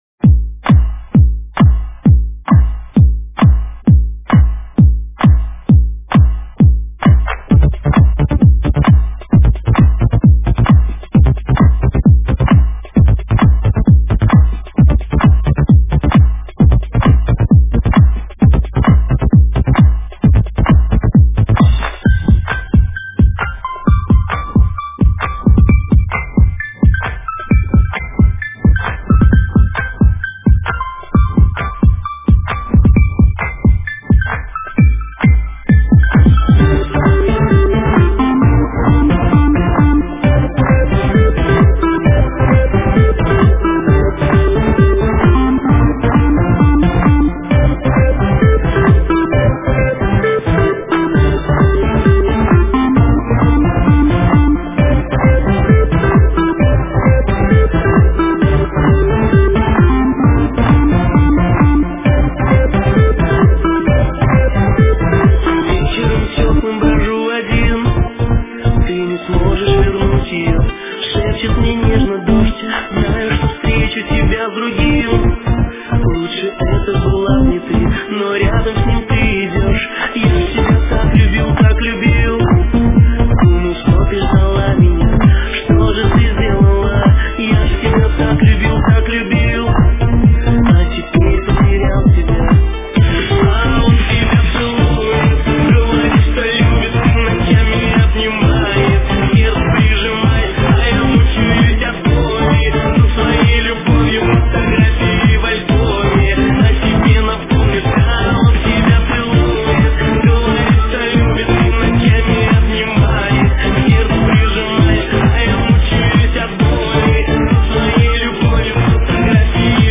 Стиль:Dance